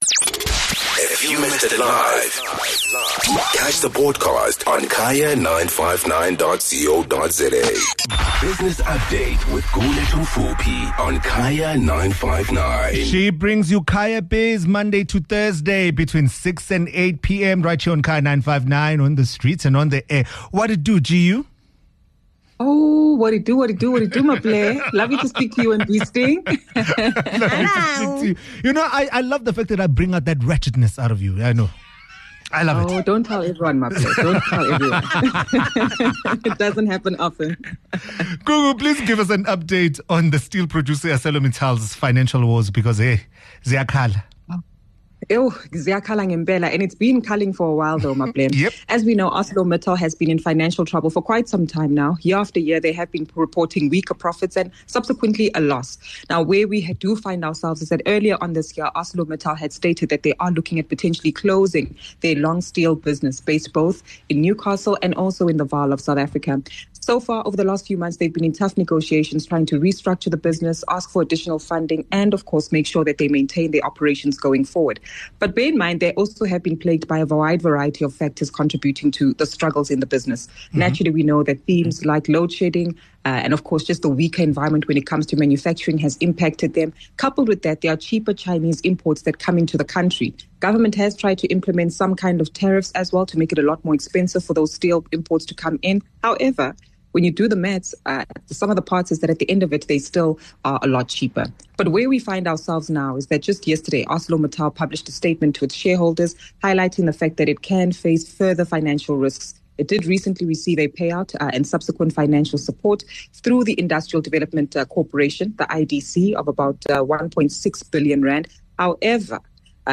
15 Jul Business Update: Arcellor Mittals financial woes